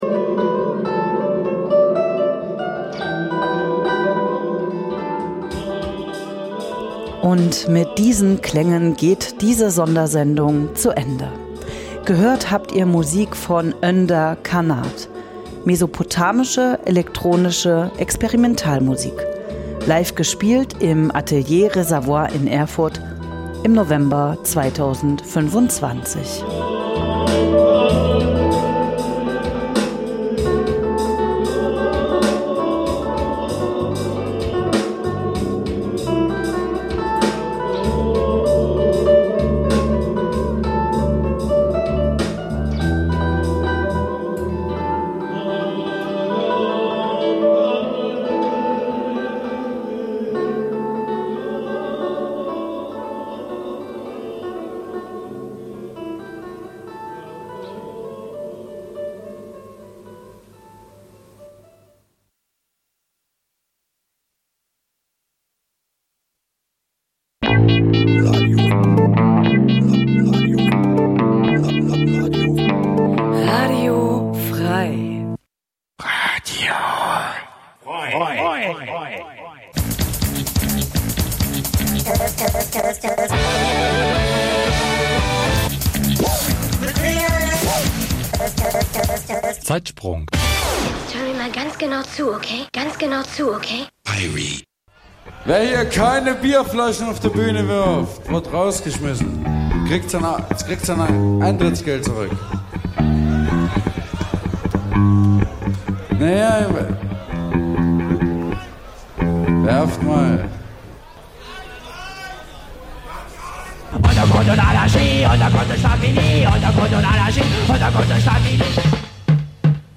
Schr�ge Originale stehen noch schr�geren Coverversionen gegen�ber.
Musik vergangener Tage Dein Browser kann kein HTML5-Audio.